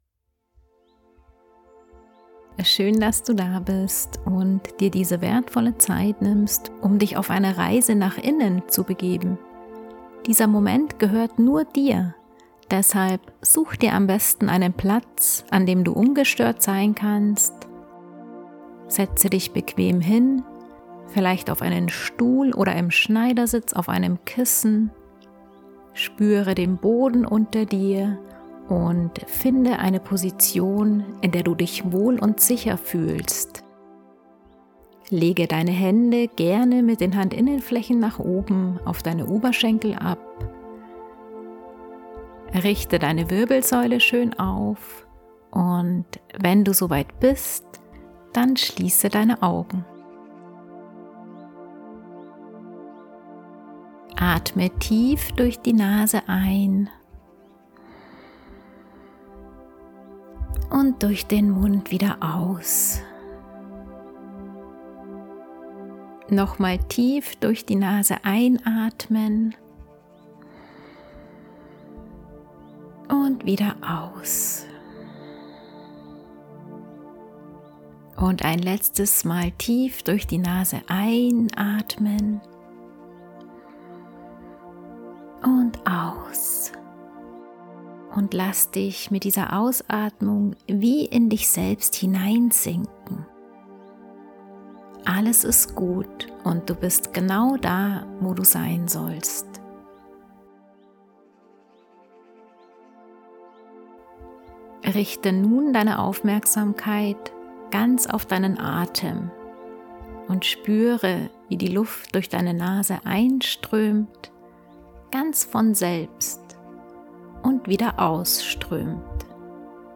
1 Meditation Atemvisualisierung: Licht einatmen, Lasten loslassen 10:36 Play Pause 12h ago 10:36 Play Pause Відтворити пізніше Відтворити пізніше Списки Подобається Подобається 10:36 Gönne Dir einen Moment der Ruhe und Verbundenheit mit dieser geführten Atemvisualisierungsmeditation.